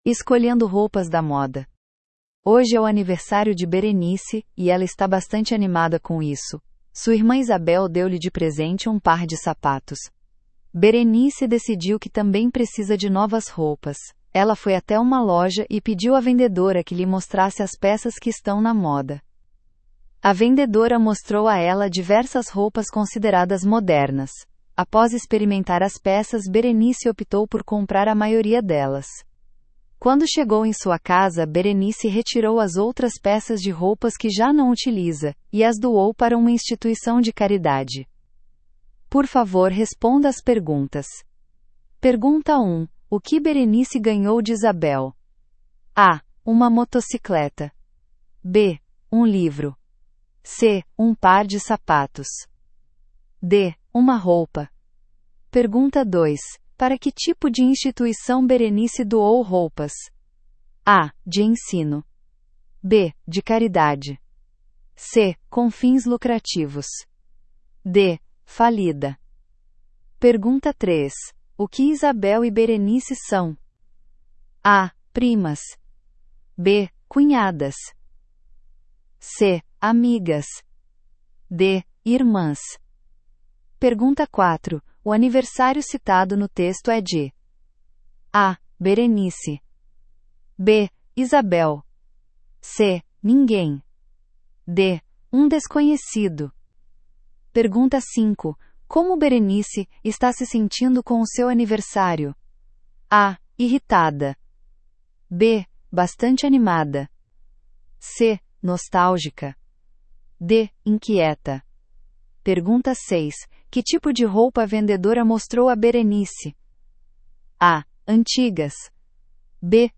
Brasile